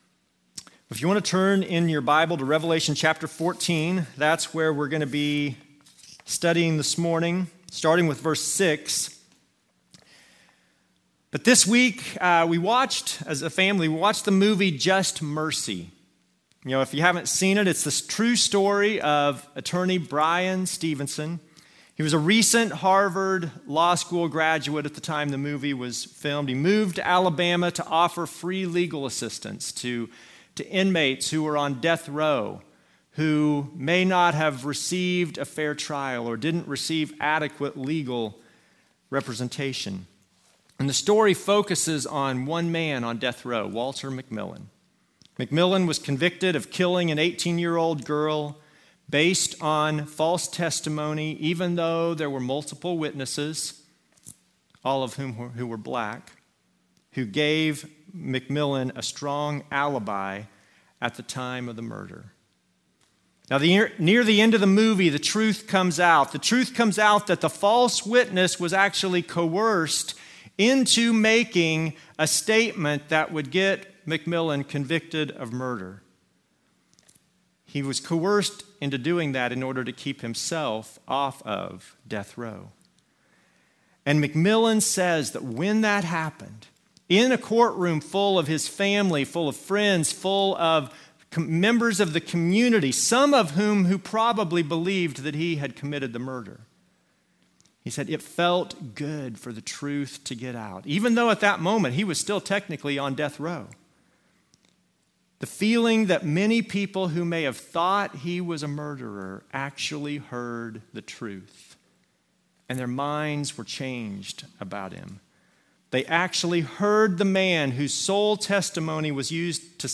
Passage: Revelation 14:6-20 Service Type: Normal service